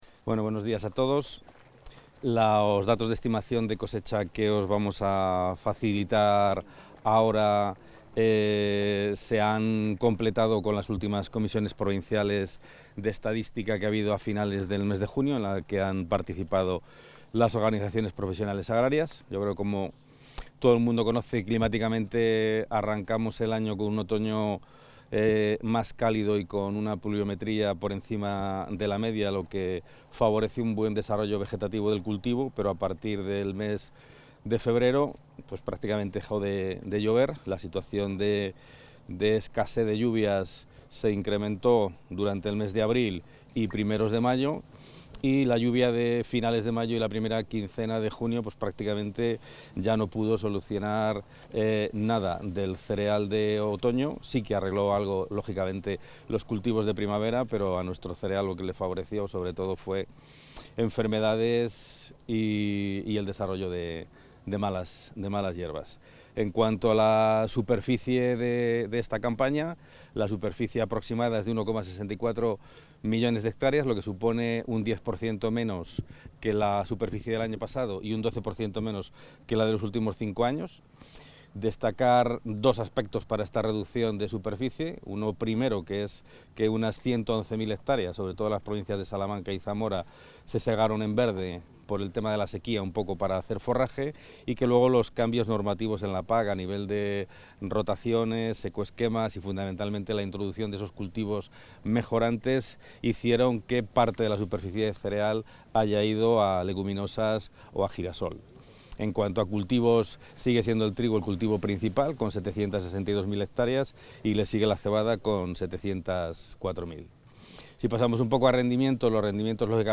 Intervención del consejero.
El consejero de Agricultura, Ganadería y Desarrollo Rural, Gerardo Dueñas, ha dado a conocer esta mañana en Calzada de Coto (León) los datos de la cosecha de cereales de invierno de 2023.